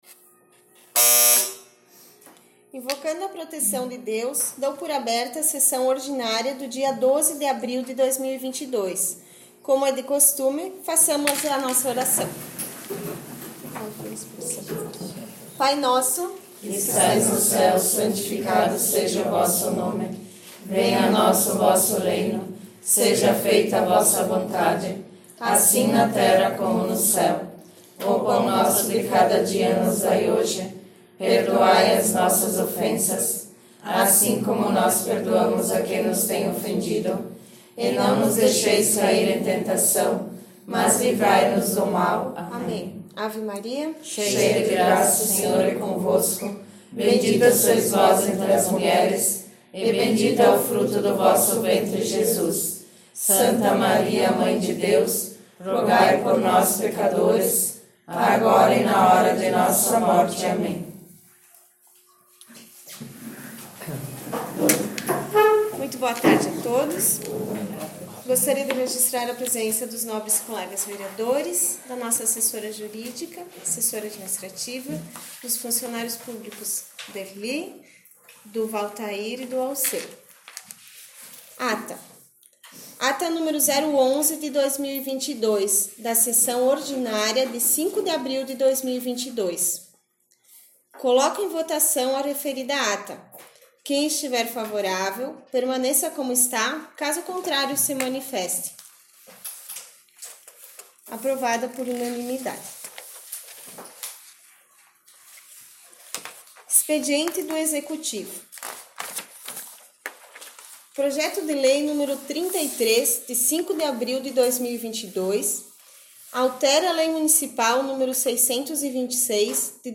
9 - Sessão Ordinária 12 de abr 17.57 — Câmara Municipal de Boa Vista do Sul
Áudio/Gravação das Sessões da Casa Legislativa Todos os Áudios ÁUDIO SESSÕES 2021 ÁUDIO DAS SESSÕES 2020 ÁUDIO DAS SESSÕES 2019 ÁUDIO DAS SESSÕES 2022 7 - Sessão Ordinária 22 de março 9 - Sessão Ordinária 12 de abr 17.57